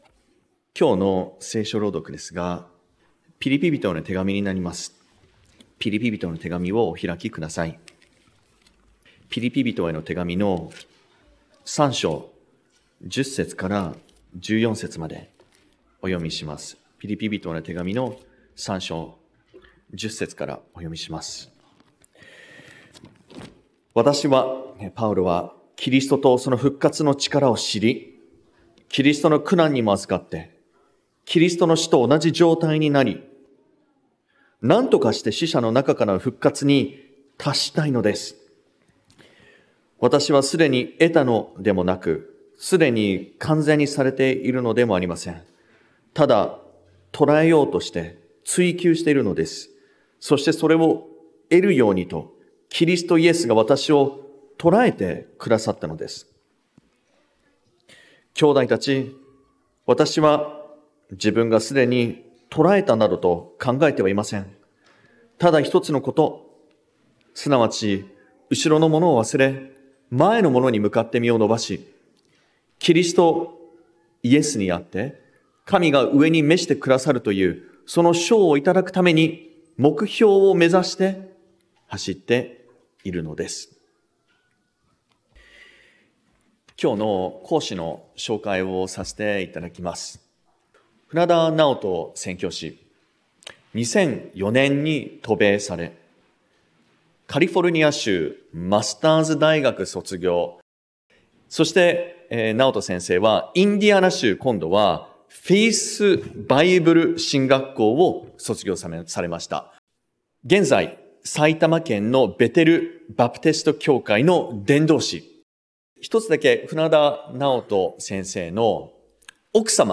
目標に向かって走る人生 説教者